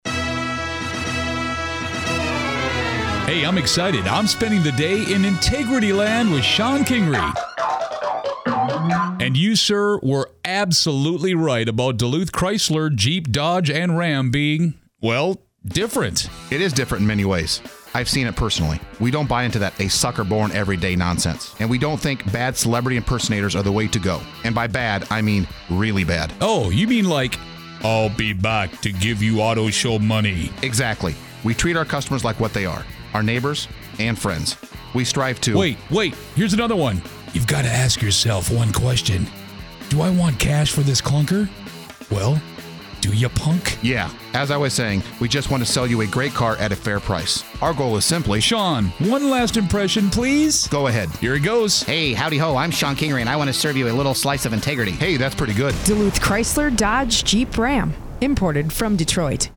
(Radio Spot #1)